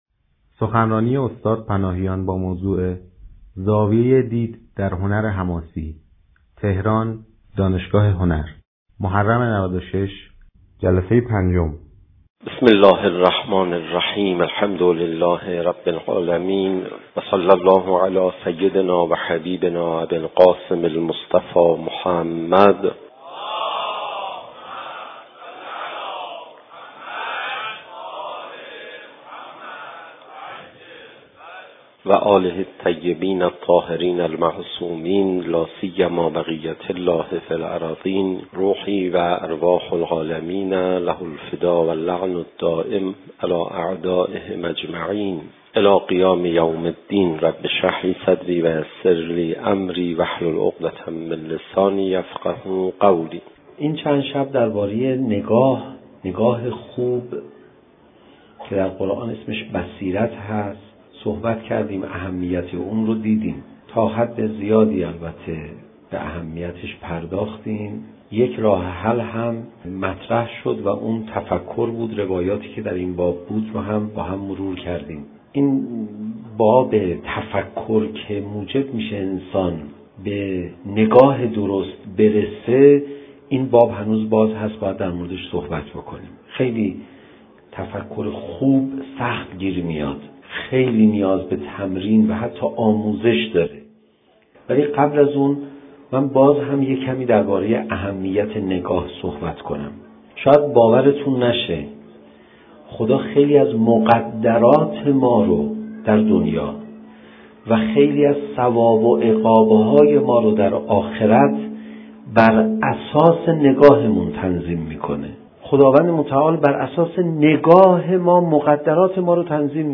سخنرانی
در دانشگاه هنر